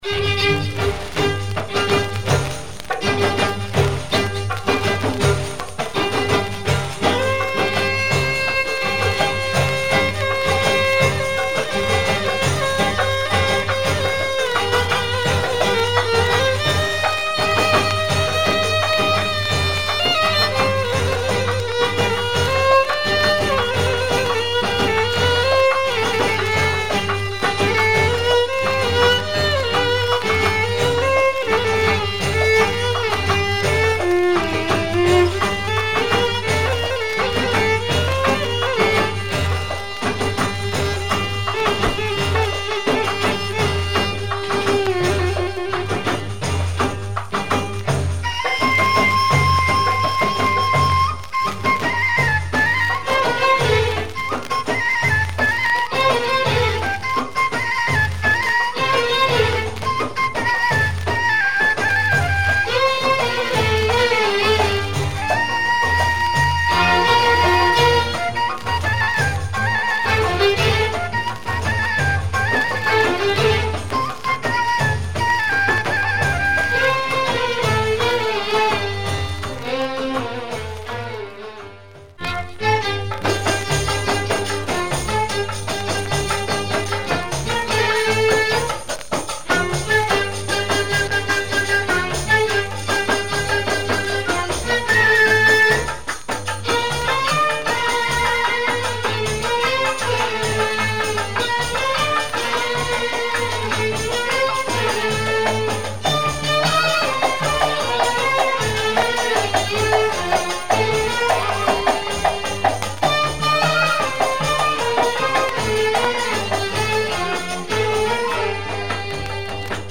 Very rare / unknown and deep 45 from 70's Morocco.